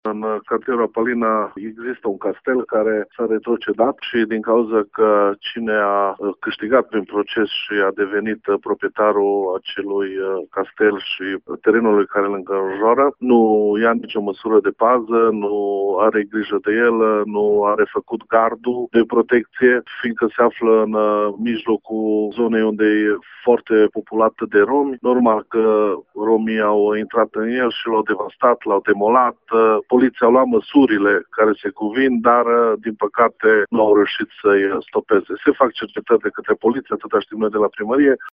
Viceprimarul municipiului Reghin, Daniel Gliga: